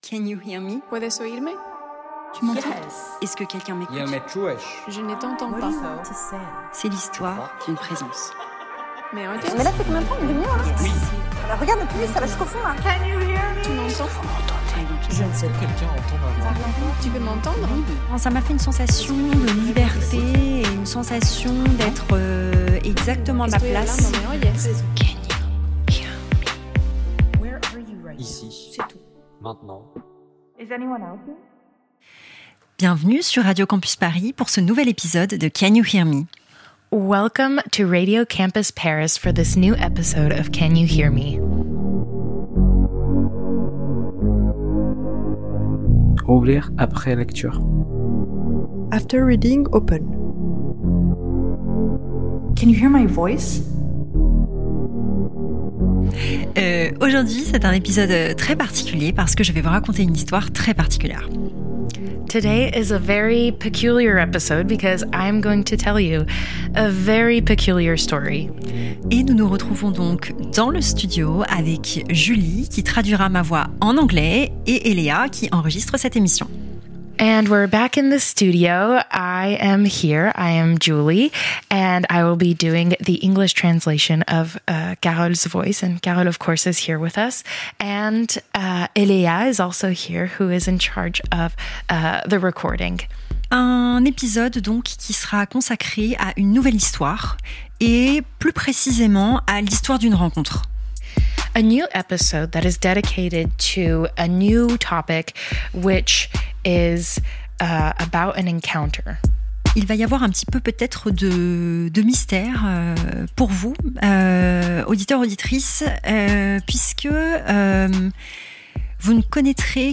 In this episode, I go to his apartment for the first time to record our conversation.
Création sonore